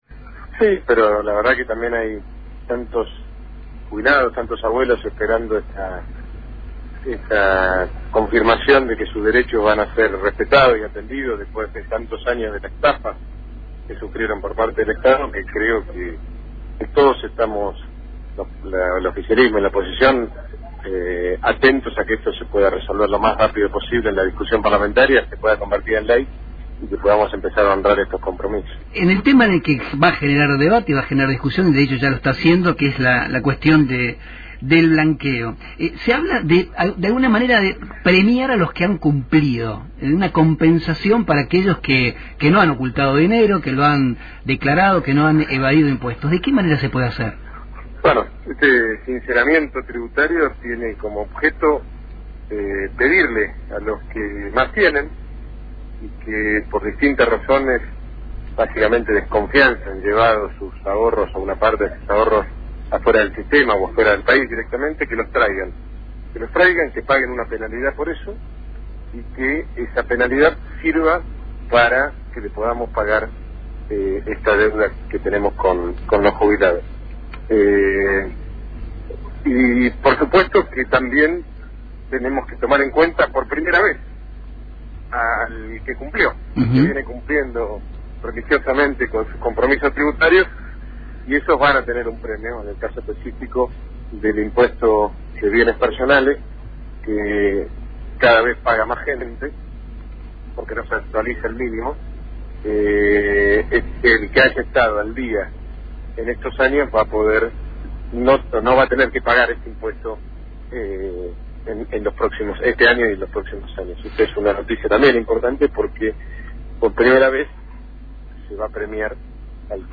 En horas en que el proyecto de pago a los jubilados y de blanqueo de capitales ingresa al Congreso, Rogelio Frigerio habló con LT10.